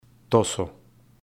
Pascual Toso Pronunciation Guide